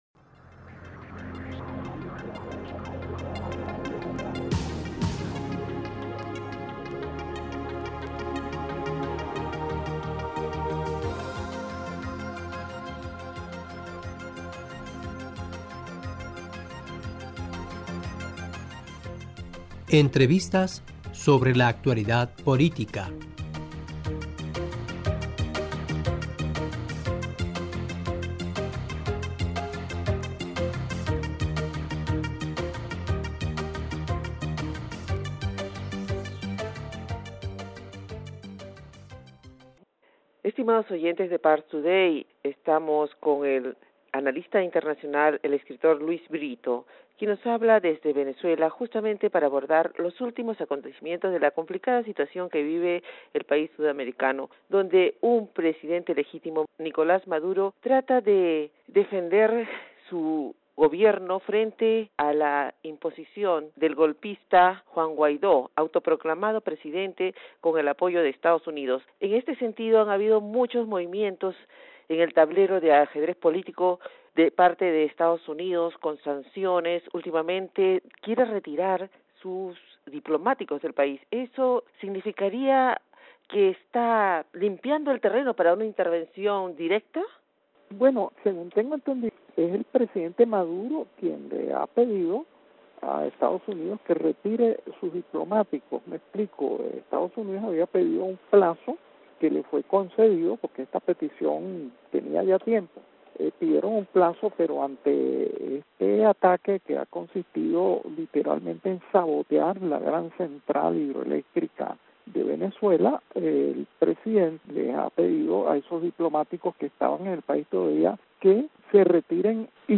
Parstoday- E: Estimados oyentes de Parstoday estamos con el analista internacional el escritor Luis Britto, quien nos habla desde Venezuela, justamente par...